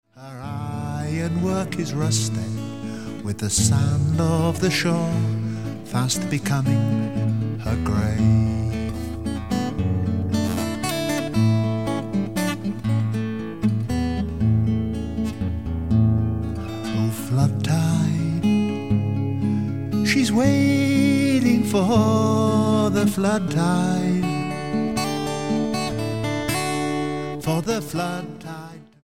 STYLE: Rock
clearly recorded on a budget